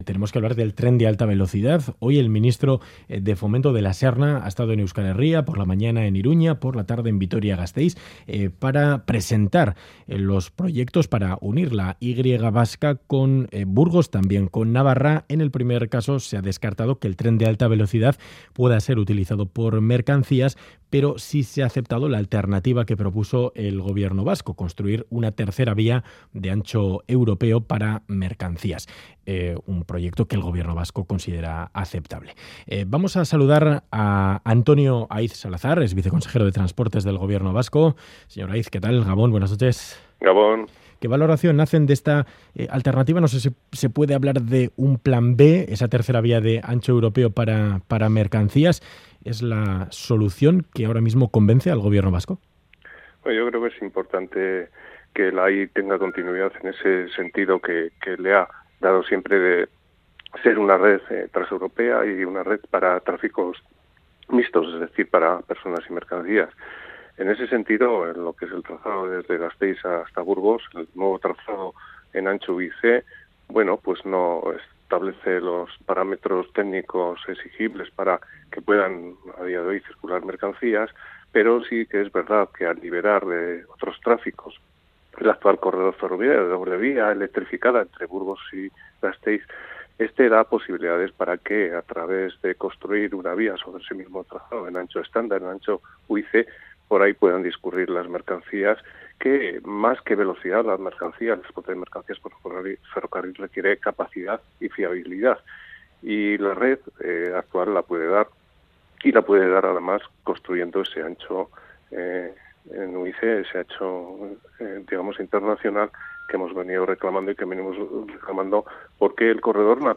Audio: Entrevista en Ganbara a Antonio Aiz Salazar, viceconsejero de Transportes del Gobierno Vasco. Considera que el corredor natural atlántico debe tener continuidad.